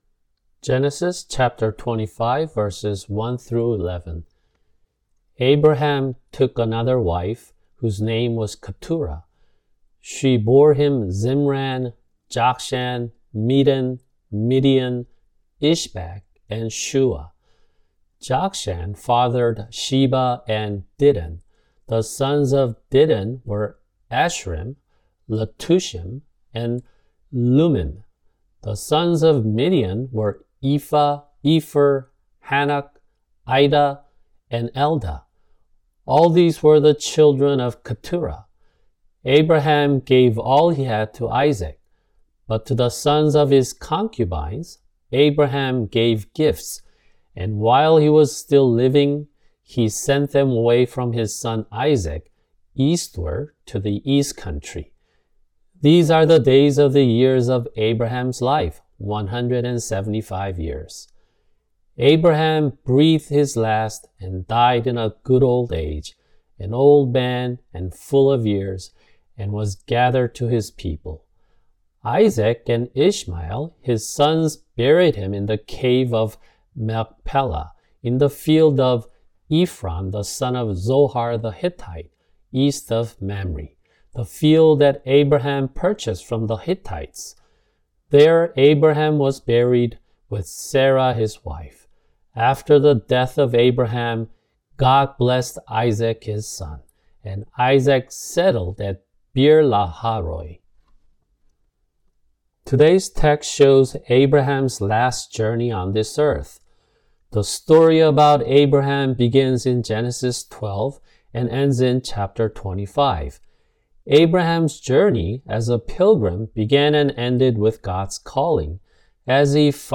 [주일 설교] 창세기 25:1-11
[English Audio Translation] Genesis 25:1-11